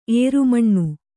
♪ ērumaṇṇu